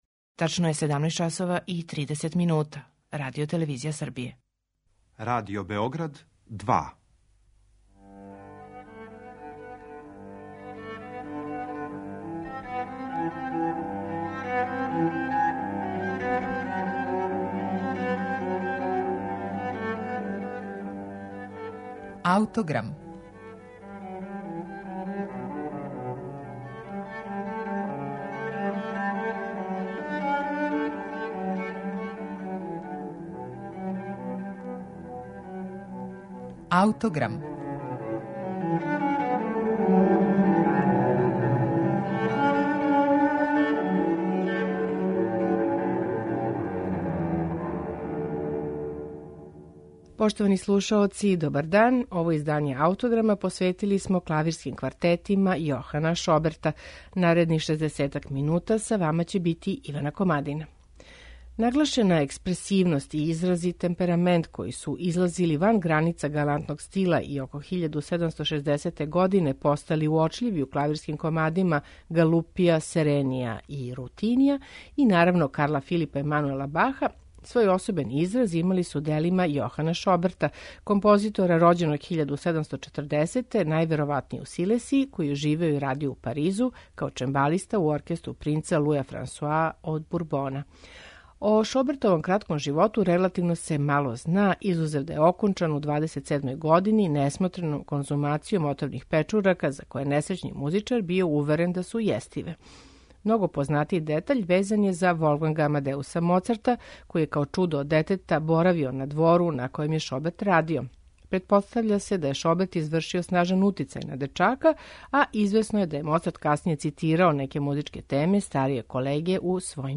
Представићемо два квартета са чембалом Јоханa Шобертa
Квартет у еф-молу
Квартет у Ес-дуру
на клавиру израђеном у Бечу 1820. године